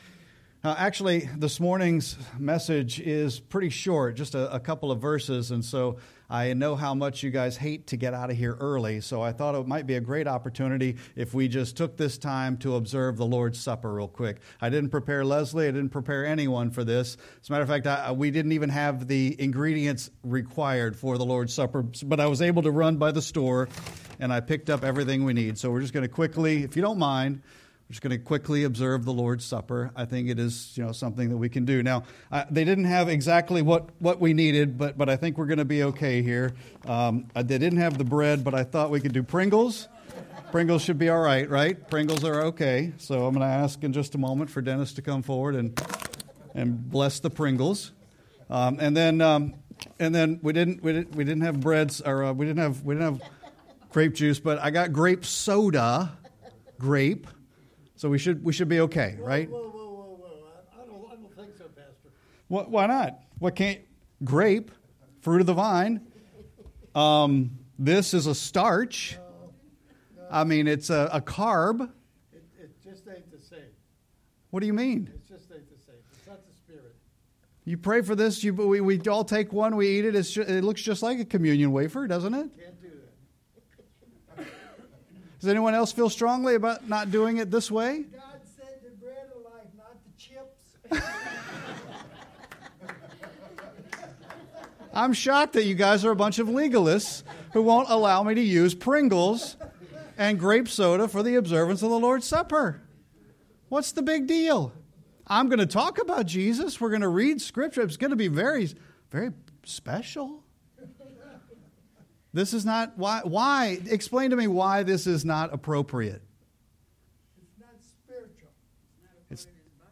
Sermon-7-6-25.mp3